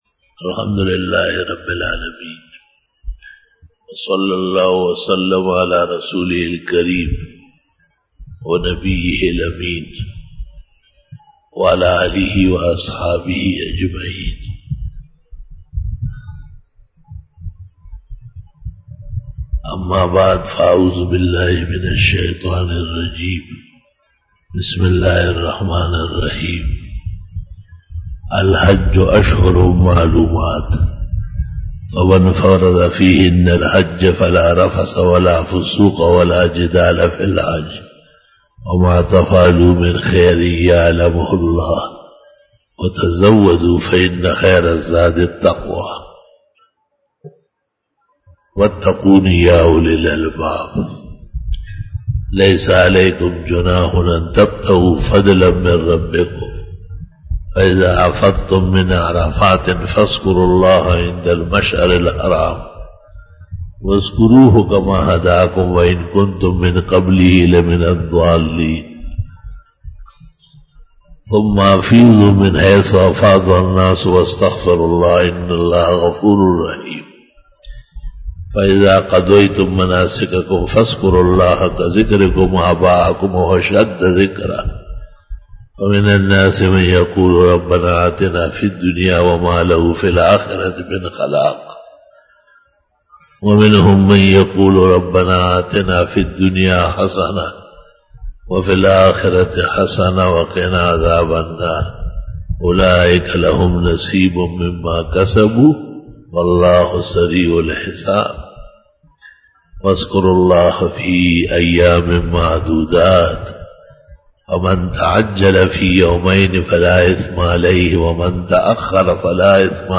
29 BAYAN E JUMA TUL MUBARAK (19 July 2019) (16 Zil Qaadah 1440H)
Khitab-e-Jummah 2019